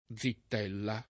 +Zitt$lla o